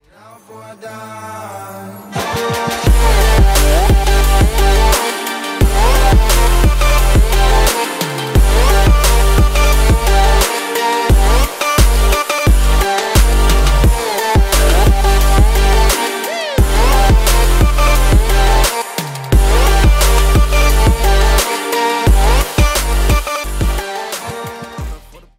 • Качество: 192, Stereo
мужской голос
мощные басы
Trap